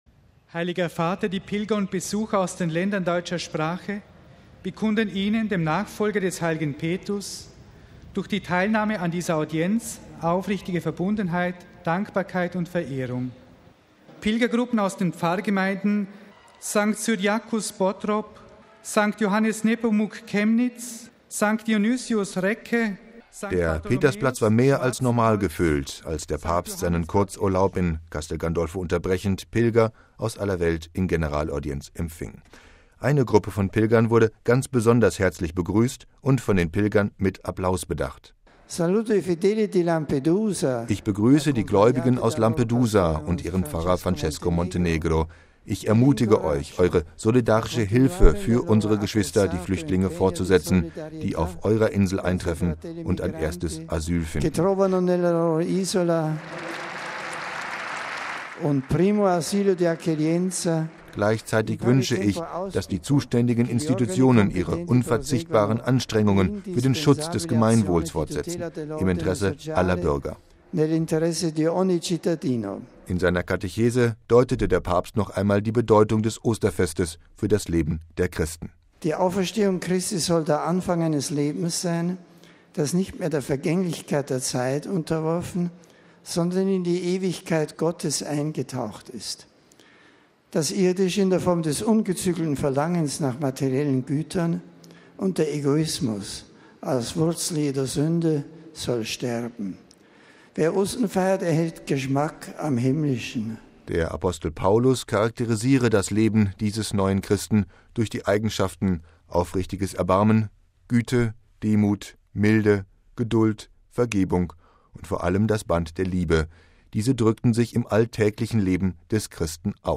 MP3 In seiner Katechese bei der Generalaudienz an diesem Mittwoch deutete Papst Benedikt XVI. noch einmal die Bedeutung des Osterfestes für das Leben der Christen: